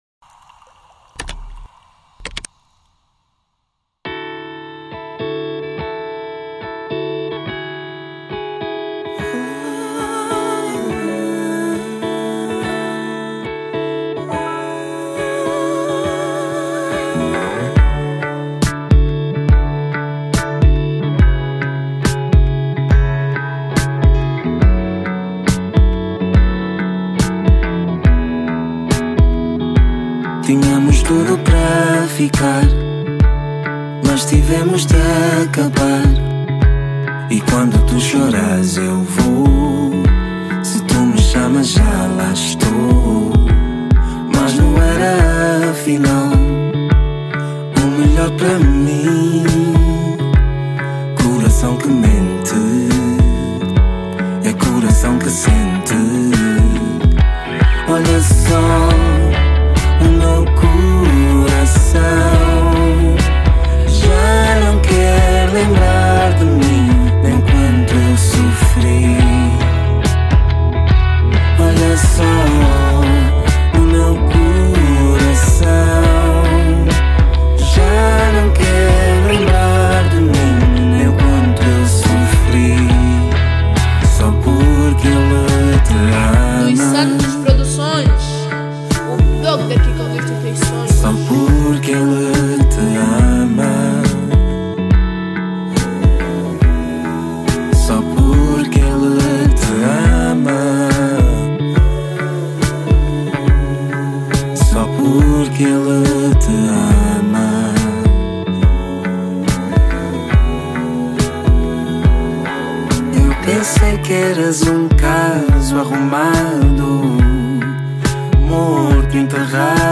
Categoria   Soul